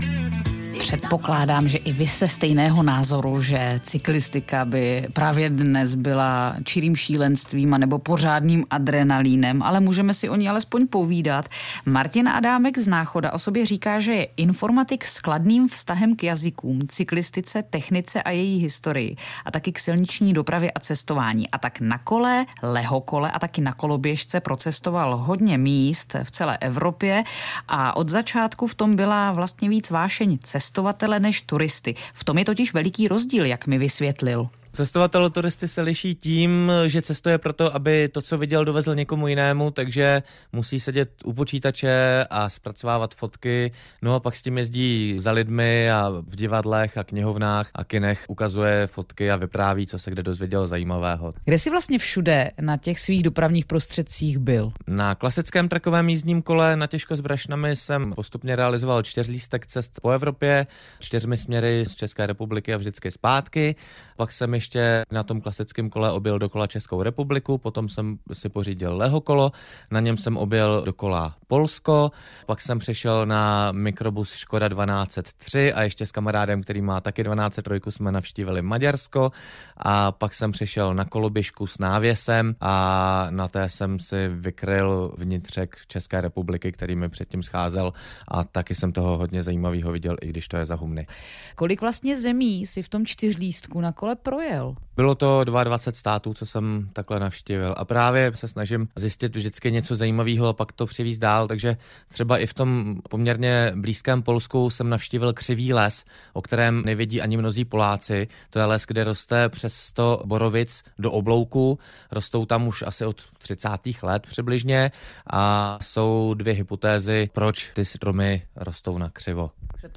Krátký rozhovor pro Český rozhlas HK:
Rozhovor o cestách a cestopisných promítáních (Formát .mp3 4.5 MiB)